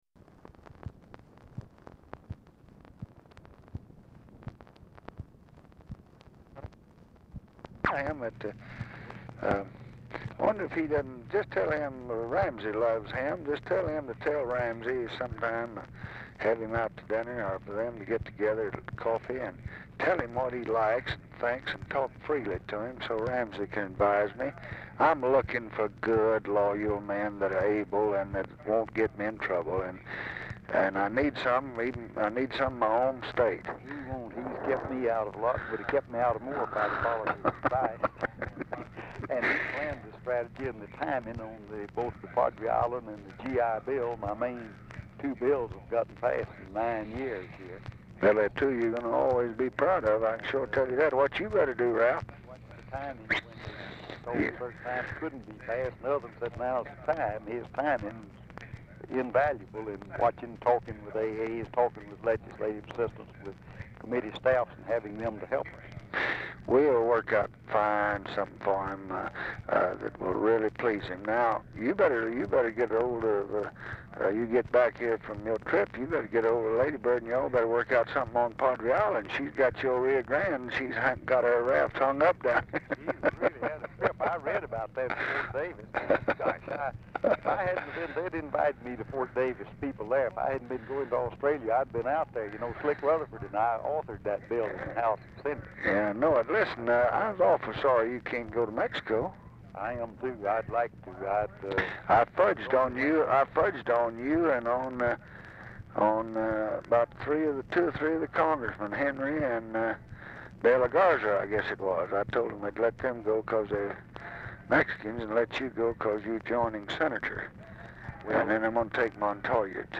TV OR RADIO AUDIBLE IN BACKGROUND
Format Dictation belt
Specific Item Type Telephone conversation